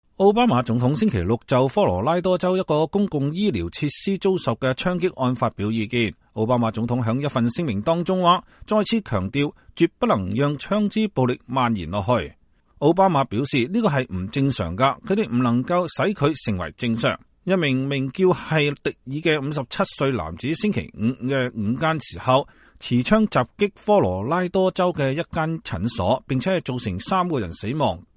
奧巴馬總統就科羅拉多槍擊案發表聲明